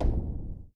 2tallHATSandPERC (3).wav